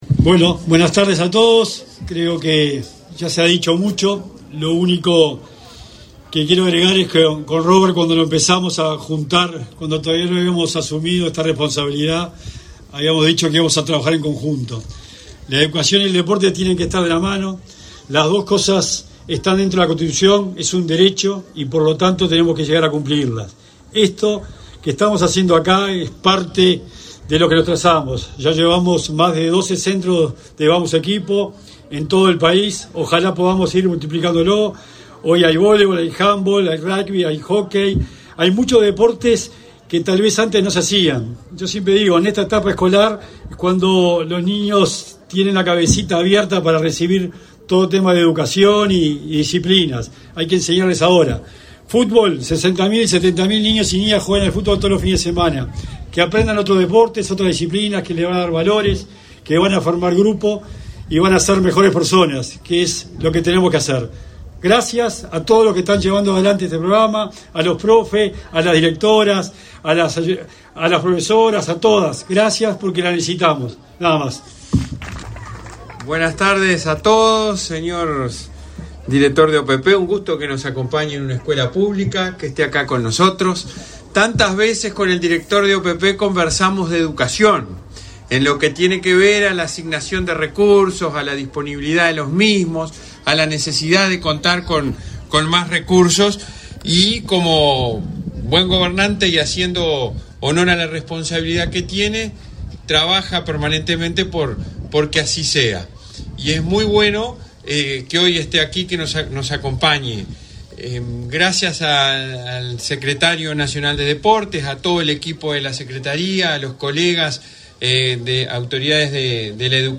Conferencia de prensa por el lanzamiento de programa deportivo en escuelas
Participaron en el evento, el secretario nacional del Deporte, Sebastián Bauzá, y el presidente de la ANEP, Robert Silva